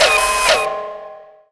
Index of /cstrike/sound/turret
tu_alert.wav